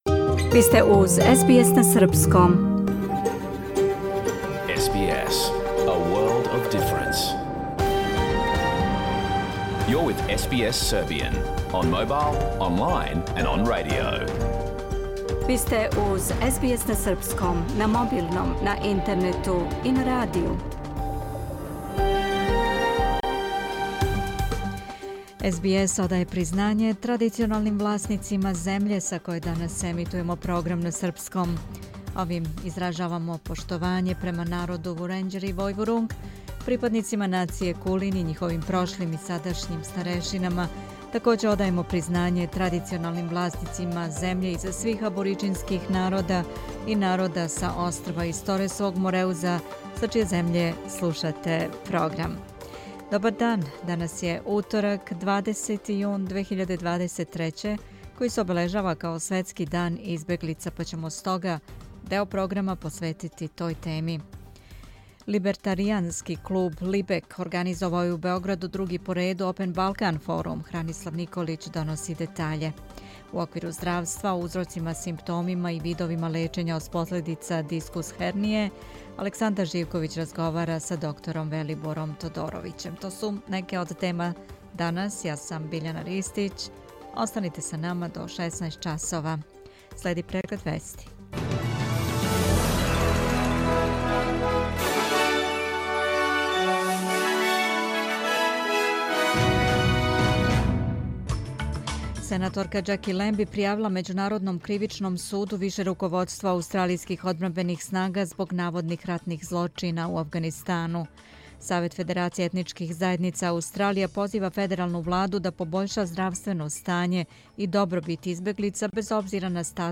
Програм емитован уживо 20. јуна 2023. године
Уколико сте пропустили данашњу емисију, можете је послушати у целини као подкаст, без реклама.